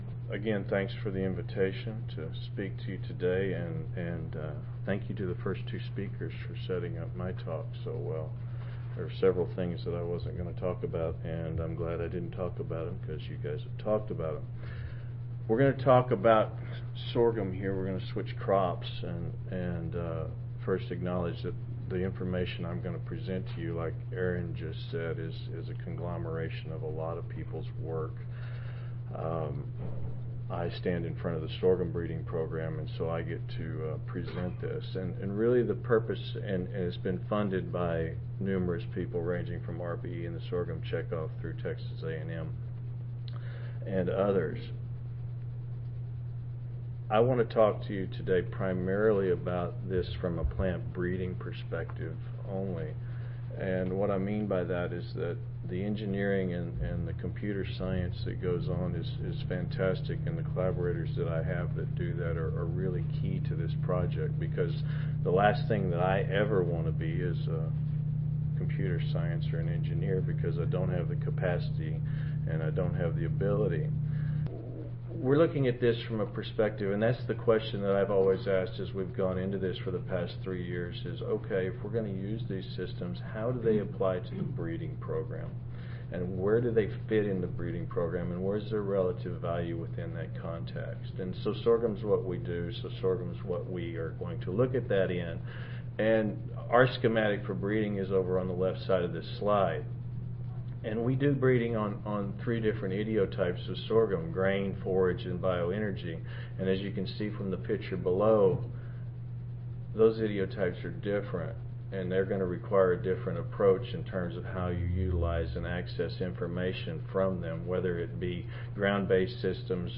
Session: Symposium--Phenotyping Plant Genetic Resources to Support Climate Smart Agriculture (ASA, CSSA and SSSA International Annual Meetings)
Recorded Presentation